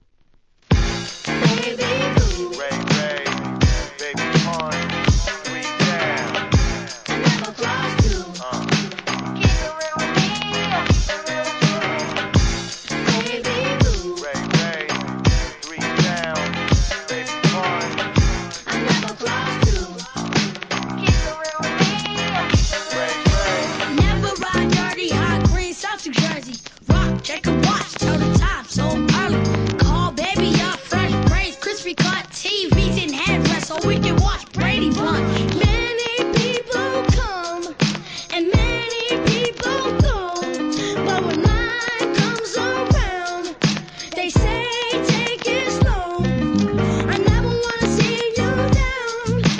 HIP HOP/R&B
*チリはサンプリングによるものです。